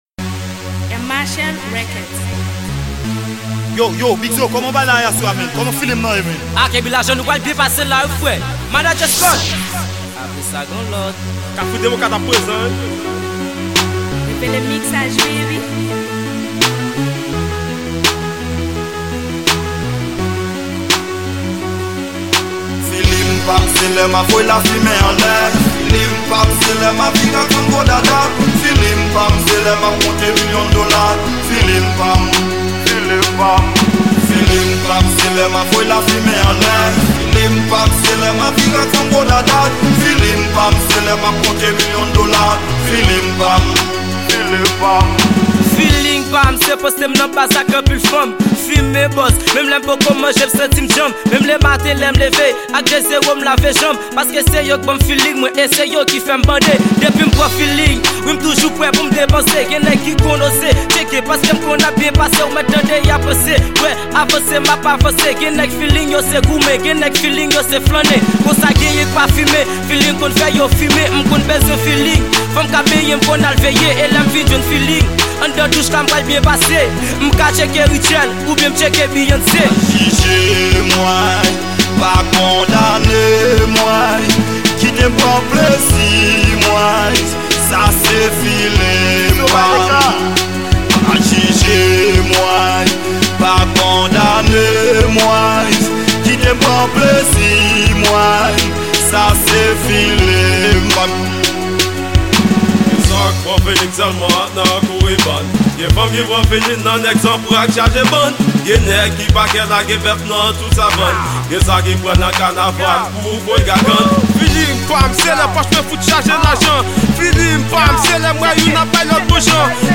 Genre: rap.’